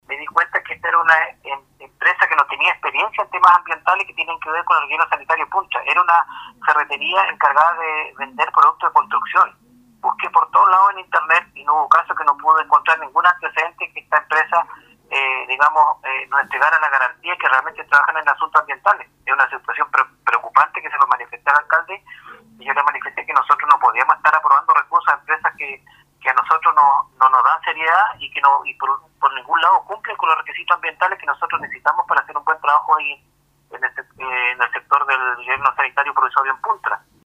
Al respecto conversamos con el concejal Alex Muñoz, quien señaló que no se pudo comprobar la experiencia de estas empresas en ejecuciones en rellenos sanitarios.